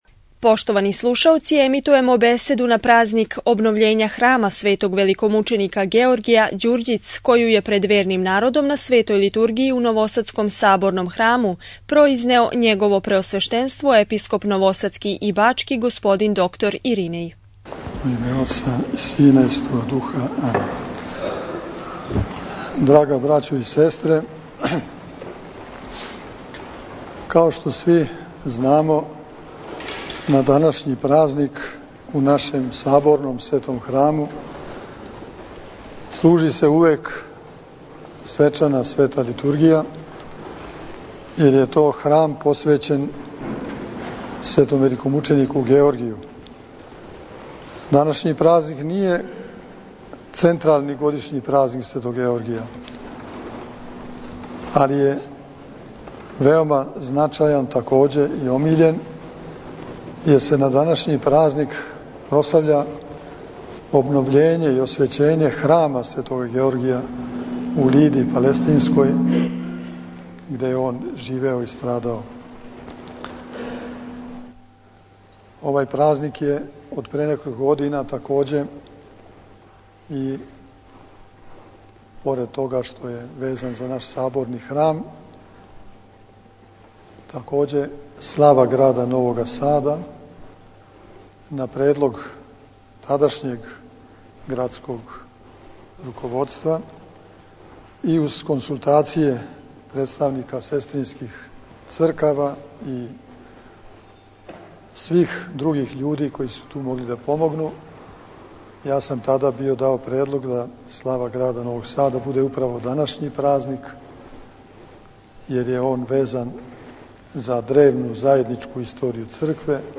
На дан славе Новог Сада, празник Обновљења храма светог великомученика Георгија, 3./16. новембра 2011. године, предстојатељ Цркве Божје у Новом Саду и Бачкој, Његово Преосвештенство Епископ бачки Господин др Иринеј, свештеноначалствовао је евхаристијским сабрањем верних у Саборном храму у Новом Саду.